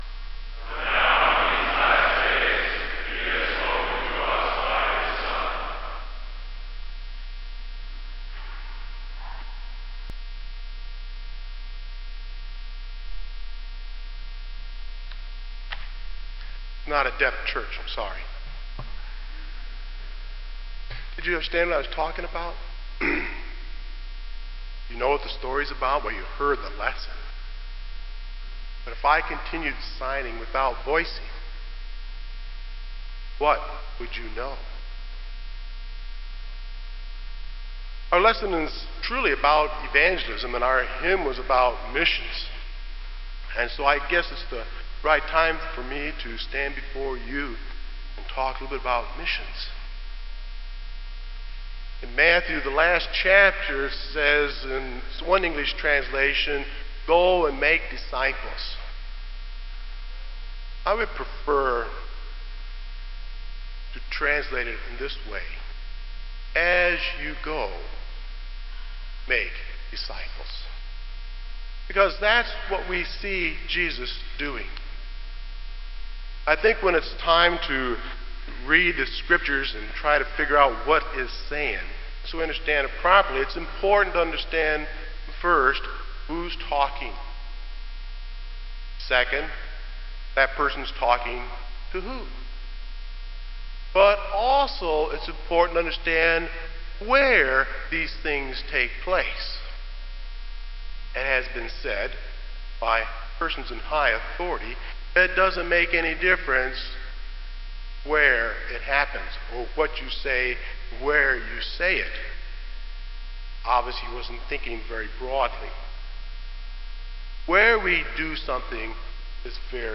Kramer Chapel Sermon - January 29, 2004